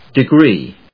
音節de・gree 発音記号・読み方
/dɪgríː(米国英語), dɪˈgri:(英国英語)/